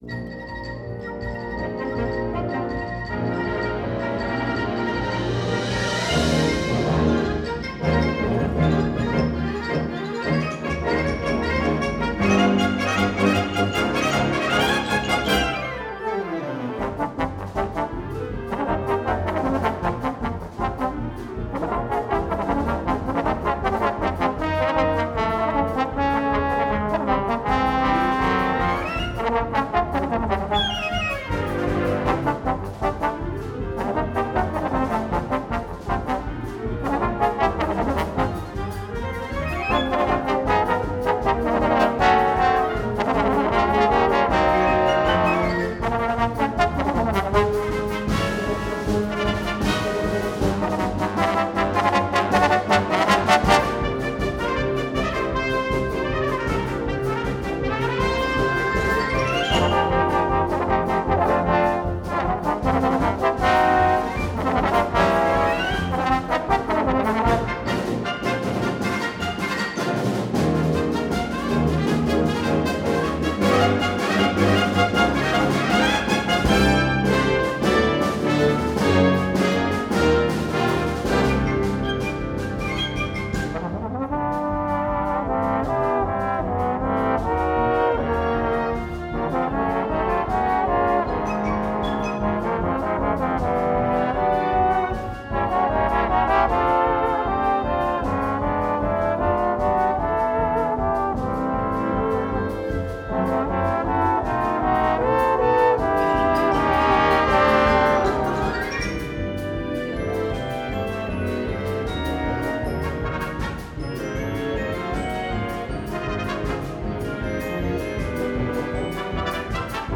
featuring the trombone section